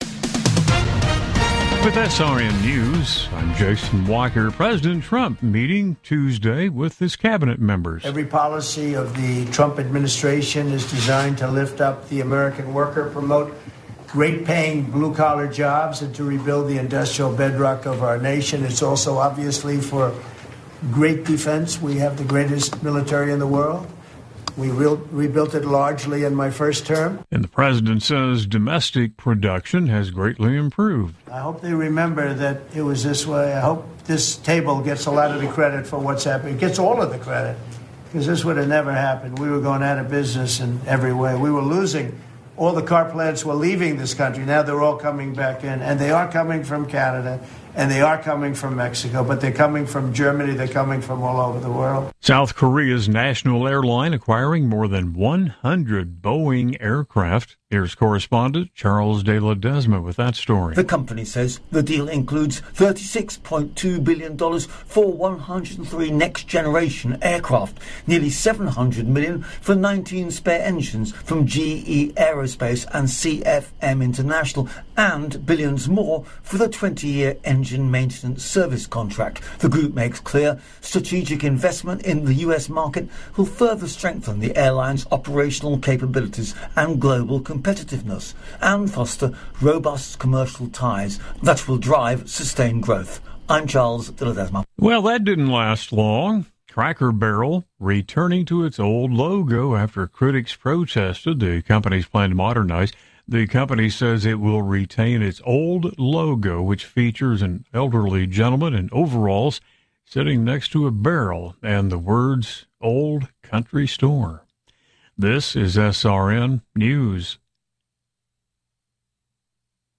Latest news stories from around the world brought to you at the top of the hour